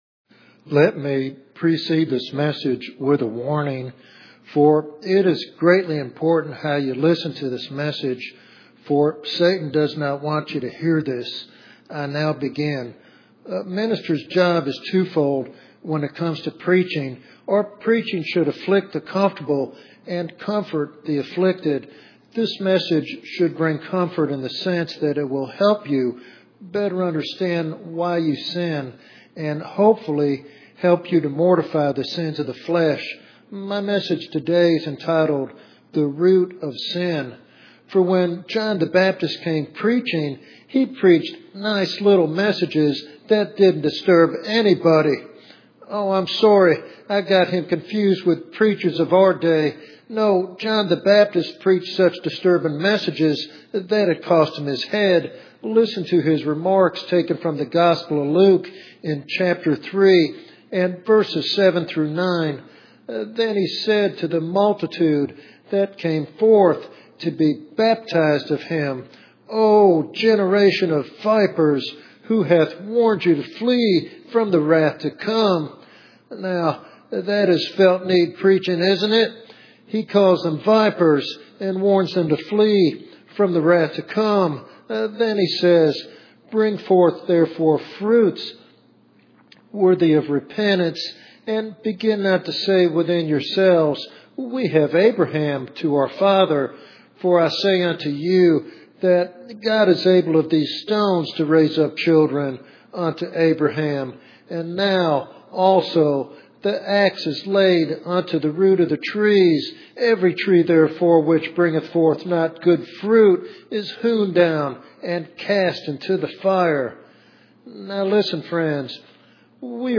This sermon encourages a deeper understanding of sin and offers hope for victory through Jesus Christ.